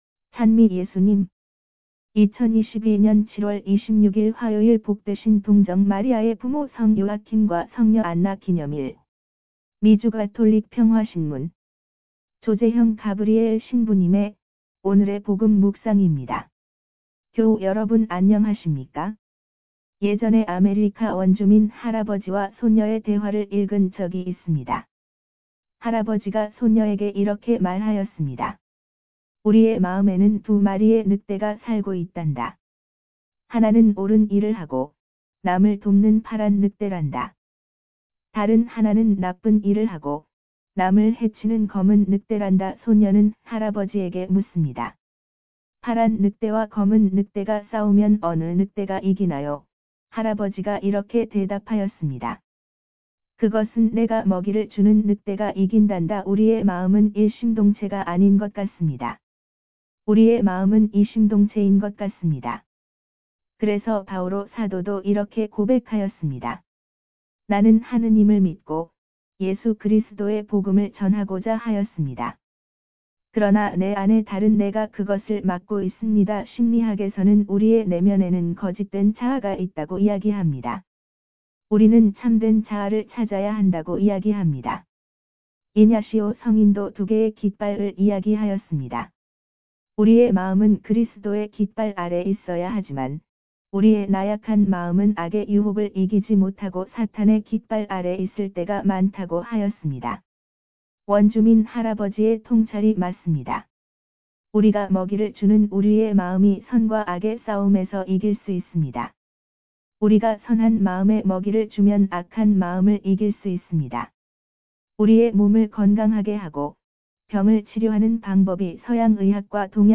강론